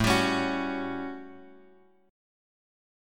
A13 Chord
Listen to A13 strummed